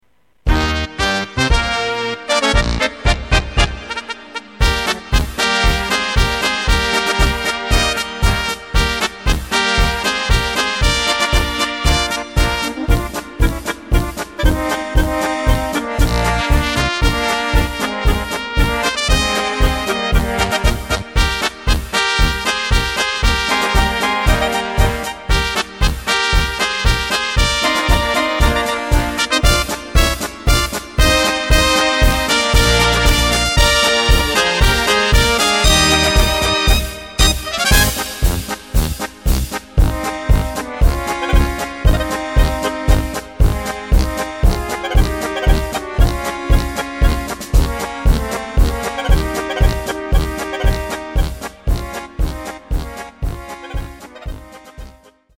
Takt:          2/4
Tempo:         116.00
Tonart:            Eb
Schöne Polka (Blasmusik) aus dem Jahr 1976!
Playback mp3 mit Lyrics